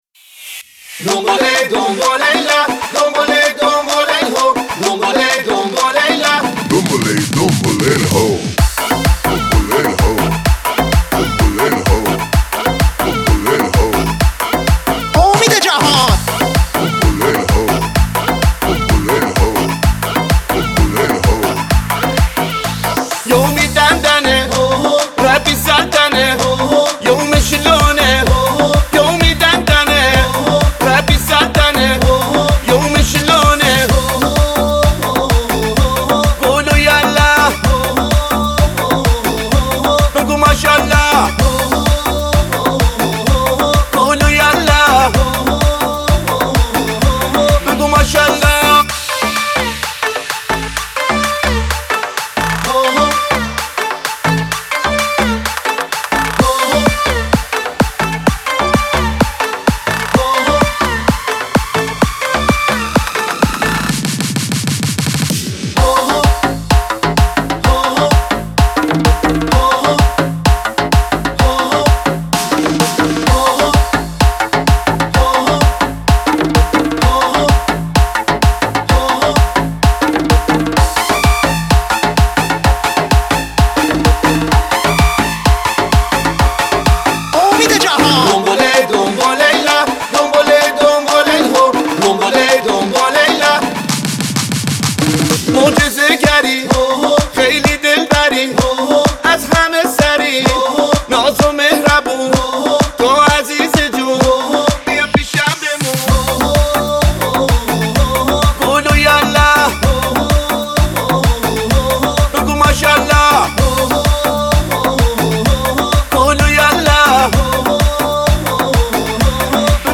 بخش دانلود آهنگ شاد آرشیو